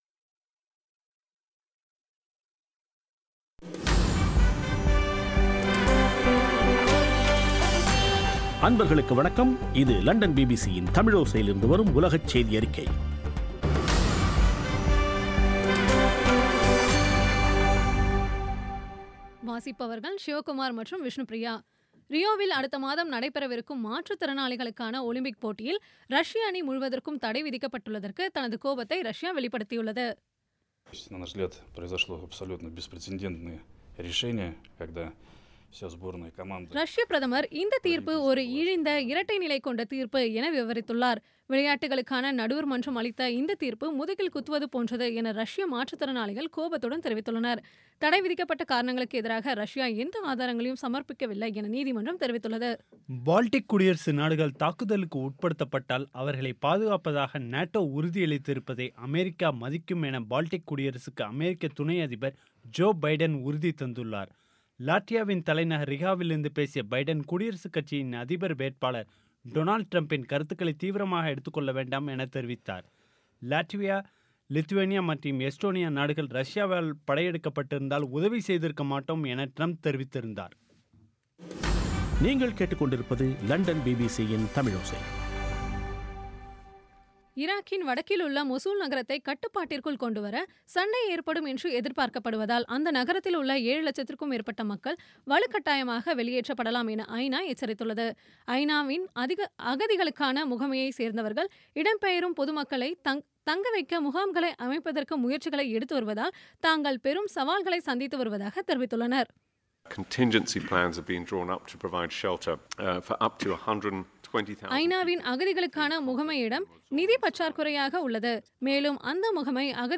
பி பி சி தமிழோசை செய்தியறிக்கை (23/08/2016)